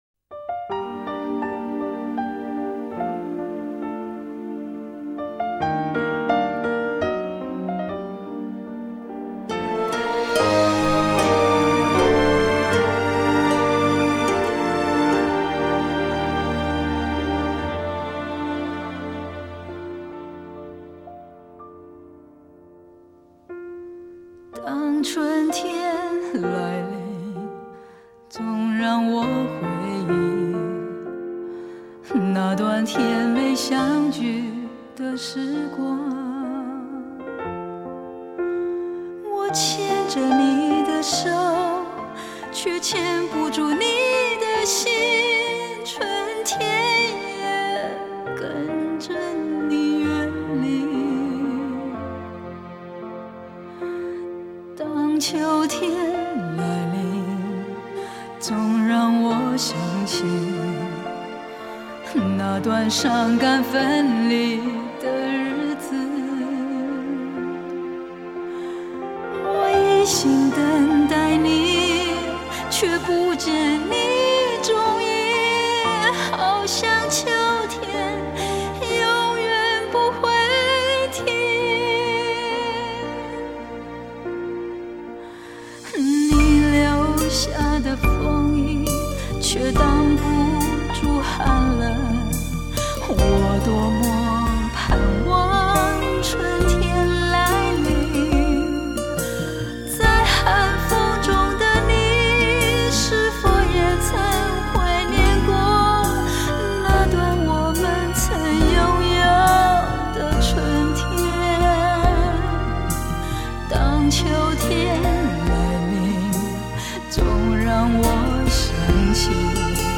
推出的一张蕴含内敛激情的专辑